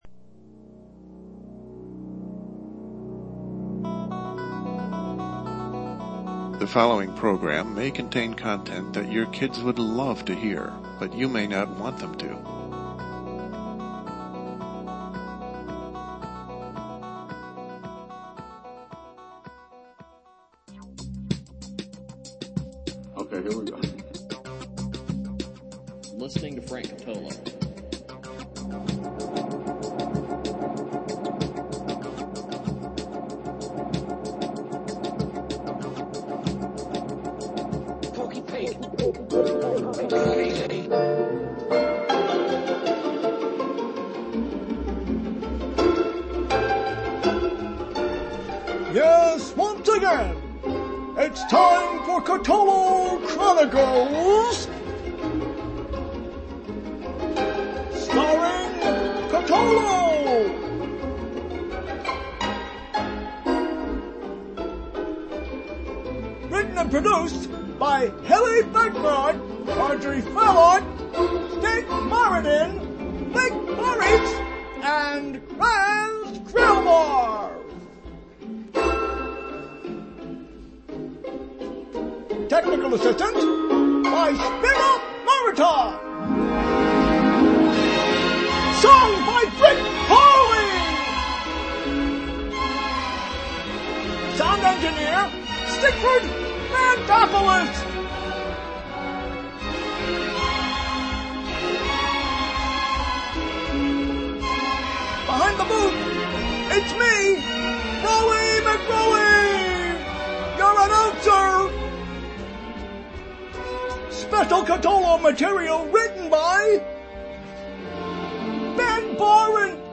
LIVE, Thursday, Nov. 29 at 9 p.m. LIVE, we explore the newest of oldest scams on the web. The Nigerian princes are back and more portals to poverty are released every day as the Internet Infidels continue to prey upon human nature.